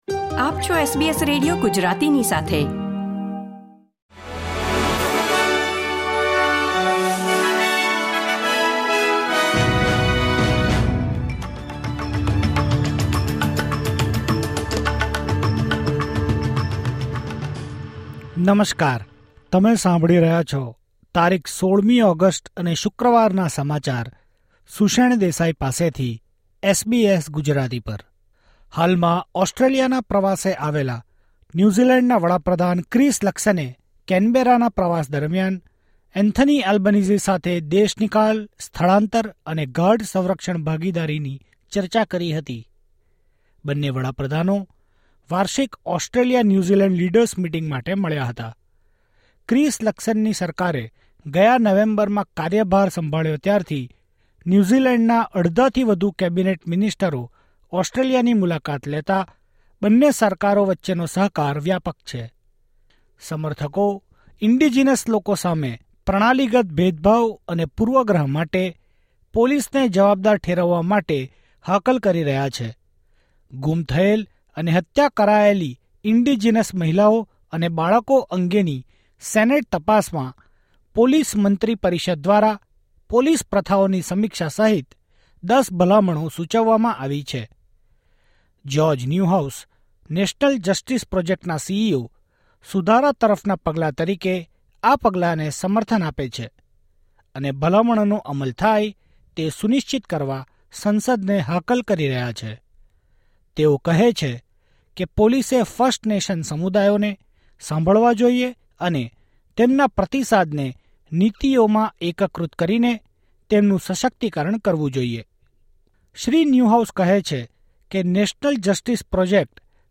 SBS Gujarati News Bulletin 16 August 2024